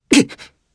Theo-Vox_Damage_jp_02.wav